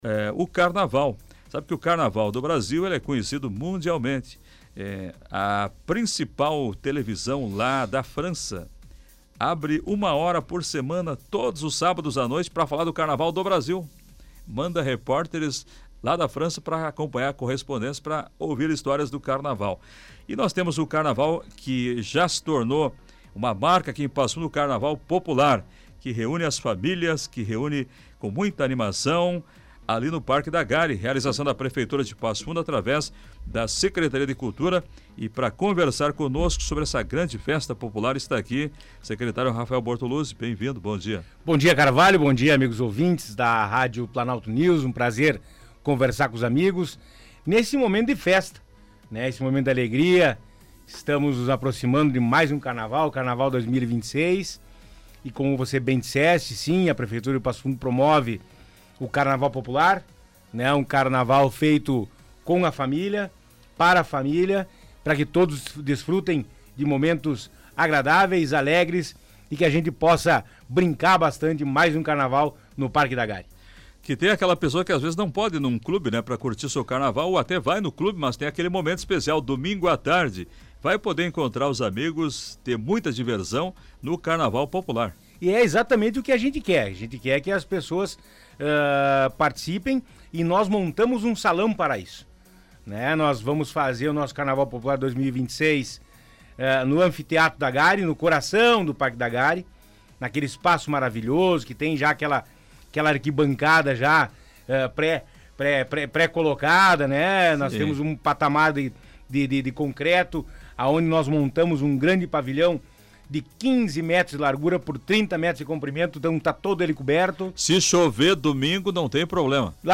Segundo o secretário de Cultura, Rafael Bortoluzzi, o Carnaval Popular representa uma forma de democratizar o acesso à folia e valorizar a produção artística local. Ele foi ouvido no programa Comando Popular, da Rádio Planalto News (92.1)